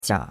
jia3.mp3